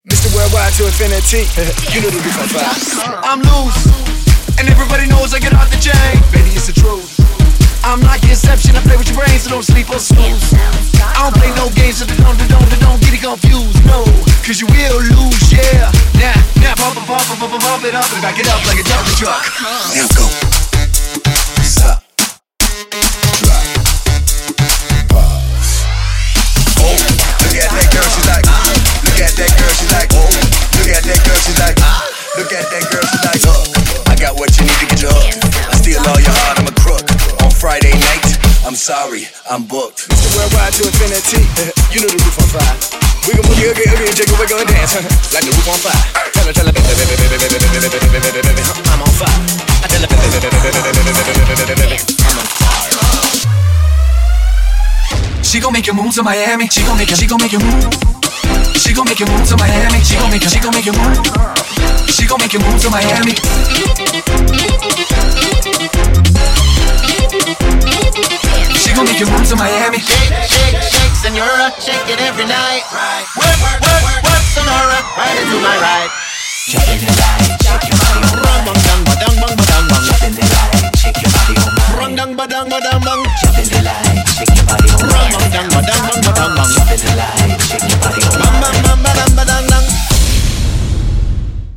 Premade Dance Music Mix